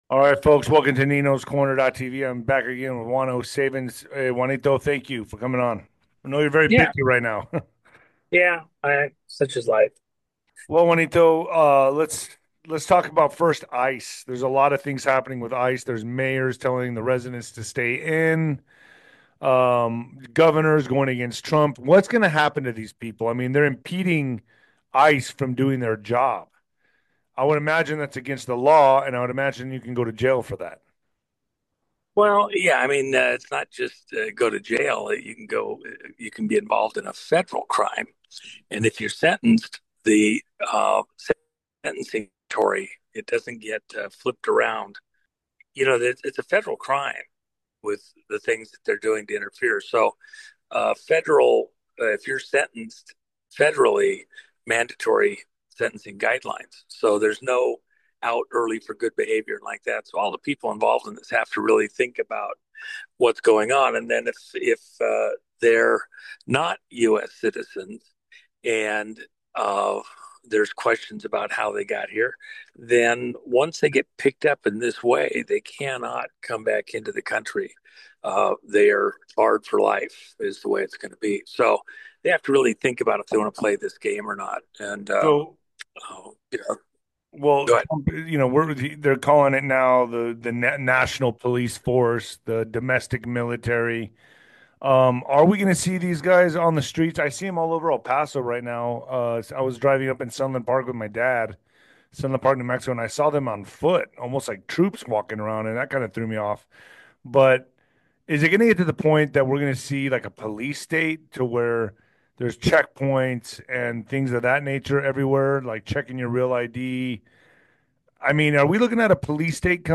On Nino's Corner TV, hosts dive into the complex topic of U.S. immigration enforcement. They explore how increased surveillance might create a temporary "police state," but could lead to better immigration control. The discussion also touches on digital data's role in job growth, birthright citizenship concerns, and ongoing global threats, including those from Iran.